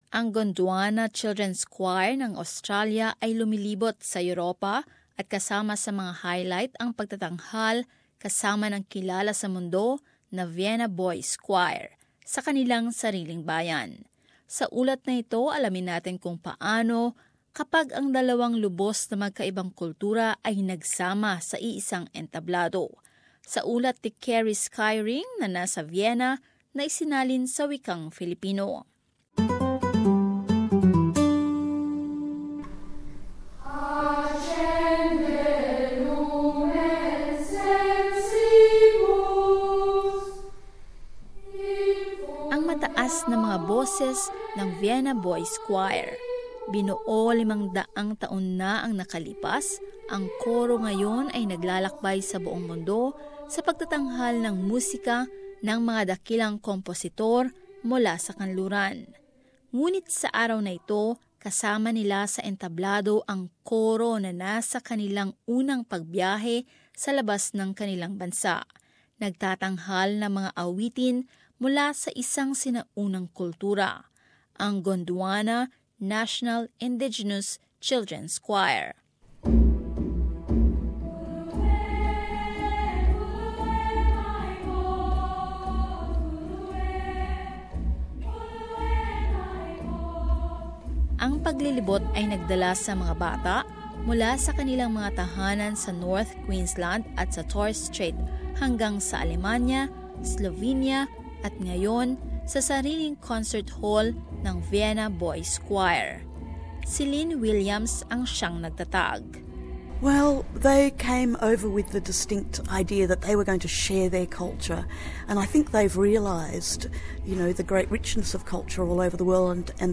Sa ulat na ito alamin natin kung paano kapag ang dalawang lubos na magkaibang kultura ay nagsama sa iisang entablado.